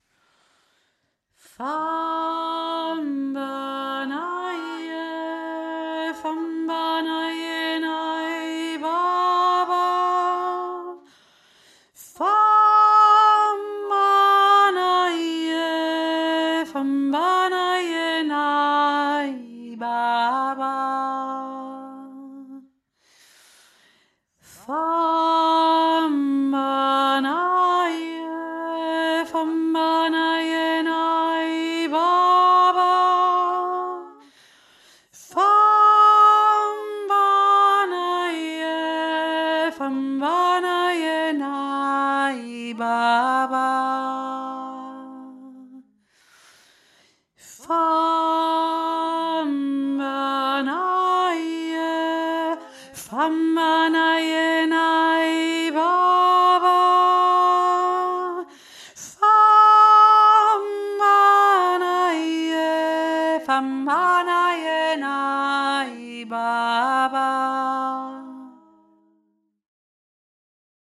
Fambanaje (südafrikanischer Gesang)
Hauptstimme
famba-naje-hauptstimme.mp3